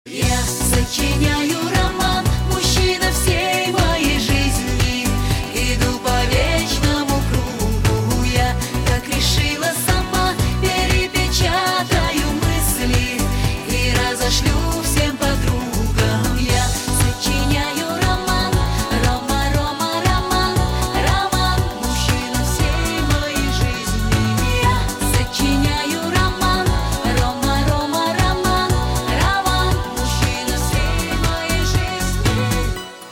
Поп музыка, Eurodance